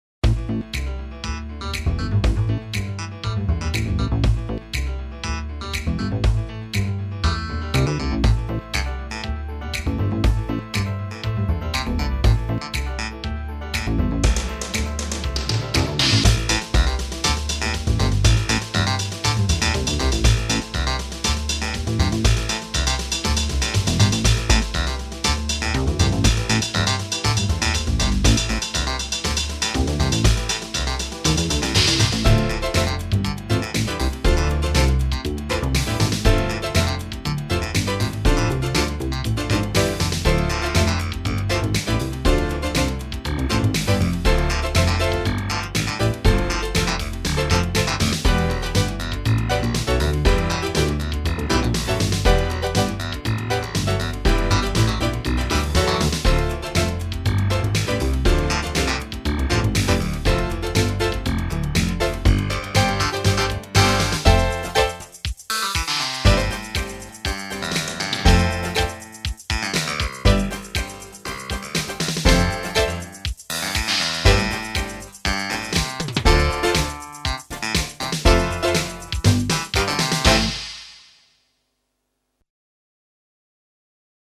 Fast, with a good clavinet groove